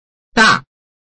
臺灣客語拼音學習網-客語聽讀拼-饒平腔-入聲韻
拼音查詢：【饒平腔】dag ~請點選不同聲調拼音聽聽看!(例字漢字部分屬參考性質)